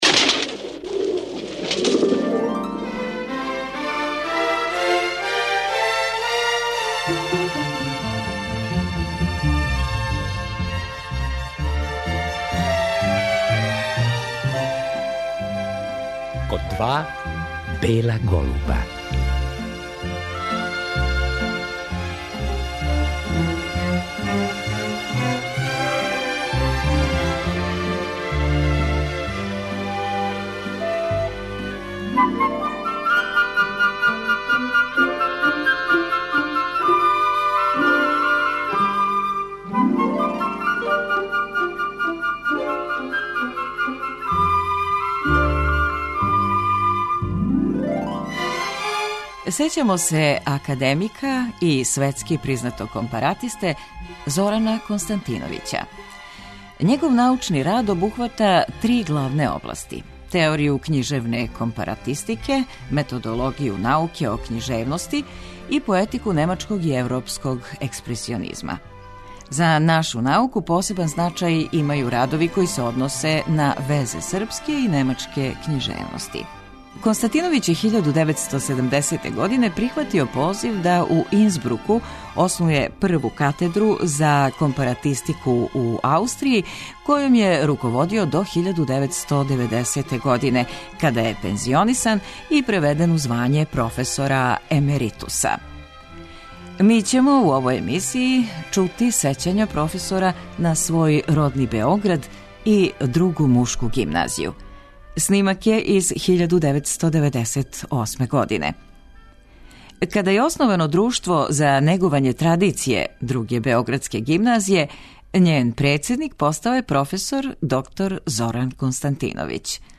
Гост ове емисије професор је био 1990.године. Говорио нам је о свом родном граду Београду и Другој мушкој гимназији.